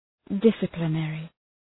Προφορά
{‘dısəplə,nerı} (Επίθετο) ● πειθαρχικός